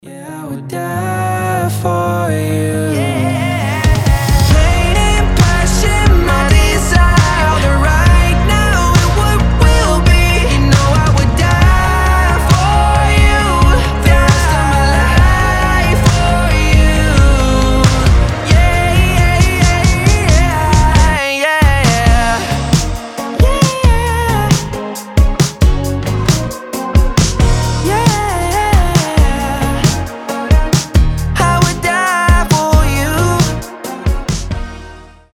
• Качество: 320, Stereo
красивый мужской голос
RnB